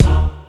Dre vocal kick.wav